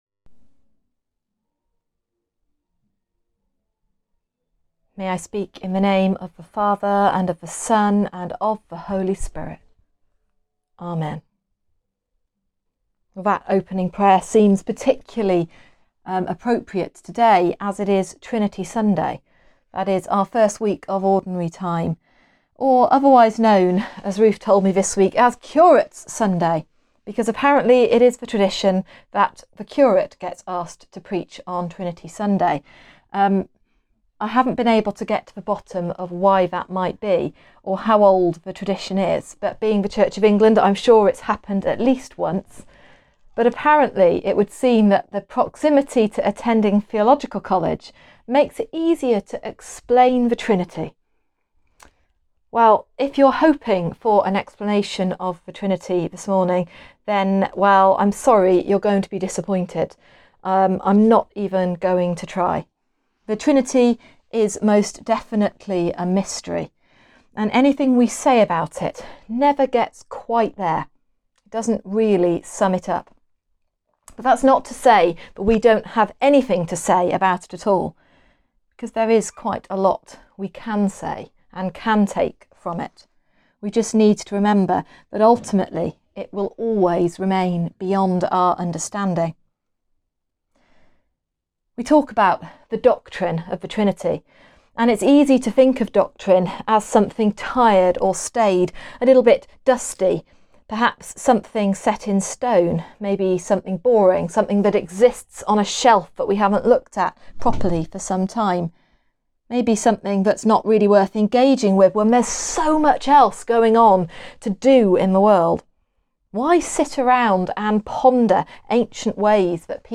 Sermon-trinity-sunday-2020.mp3